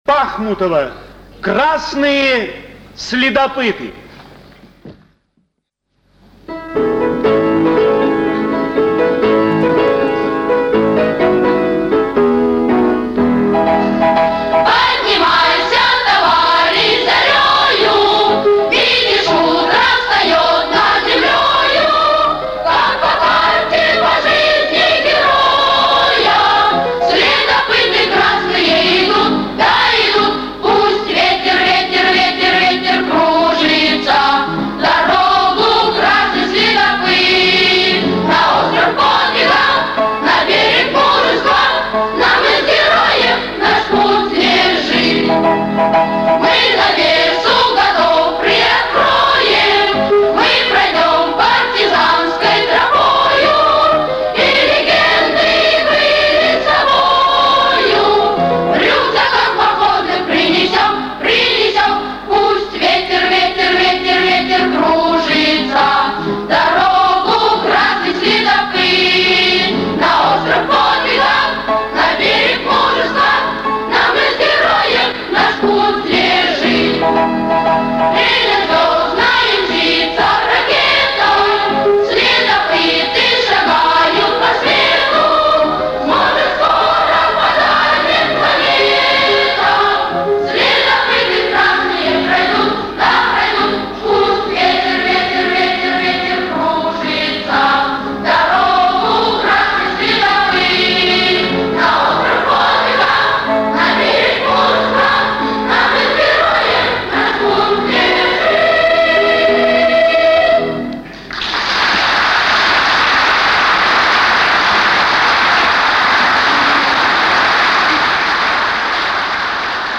Запись с концерта.